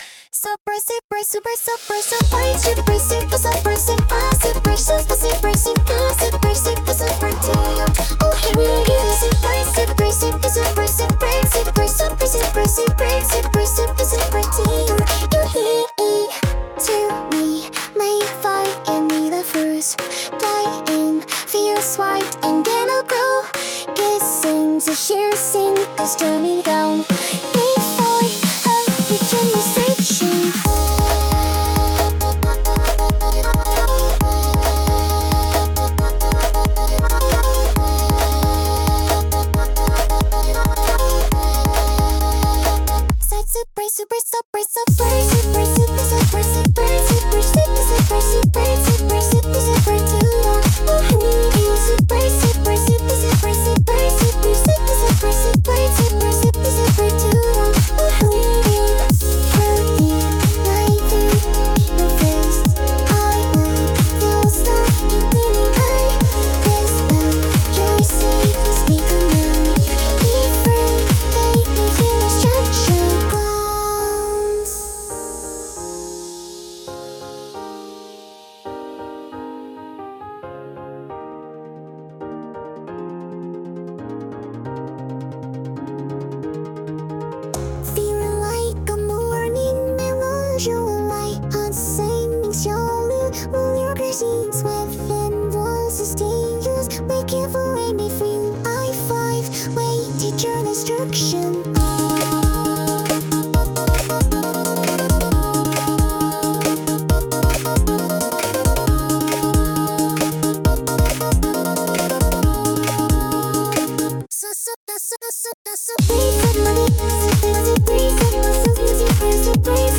• ジャンル：エラーポップアイドル
• 声：高めでちょっとクセあり／語尾が飴っぽい